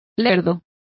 Complete with pronunciation of the translation of oafish.